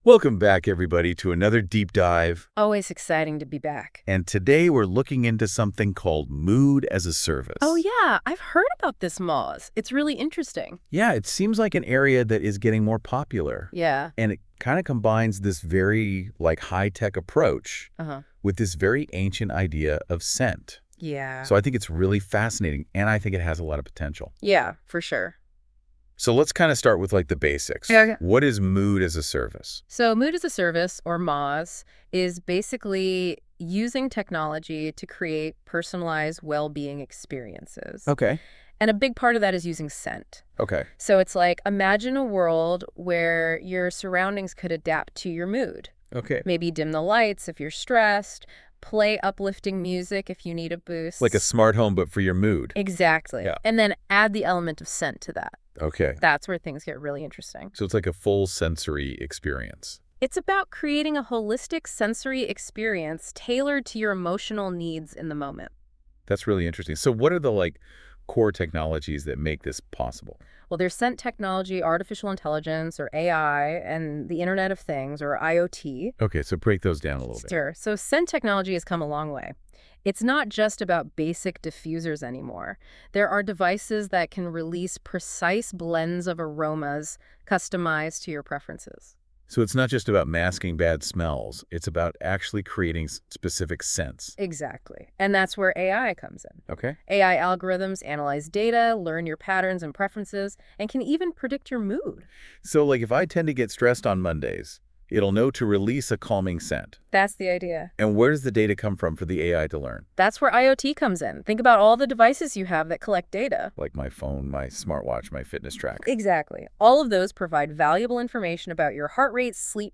In our case, NotebookLM became a co-host , helping us communicate how people use our Mood as a Service to transform their lives.
The podcast banter is far from perfect.